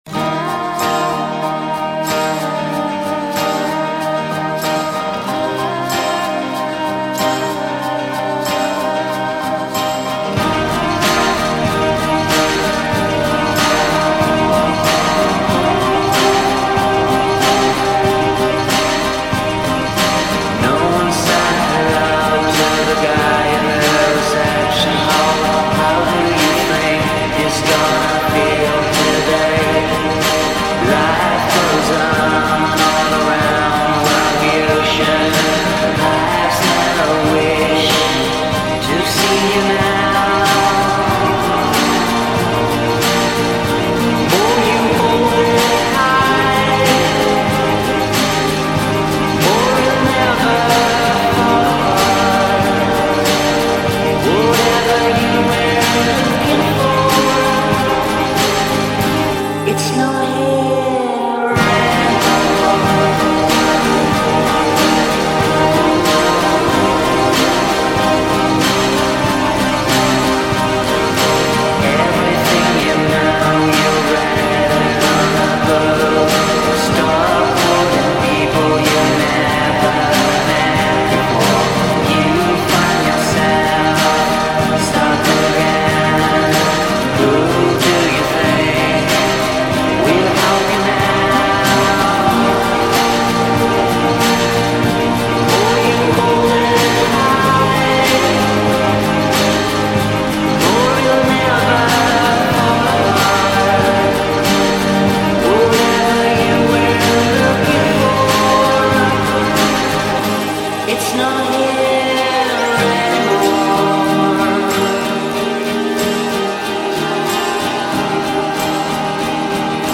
verträumten song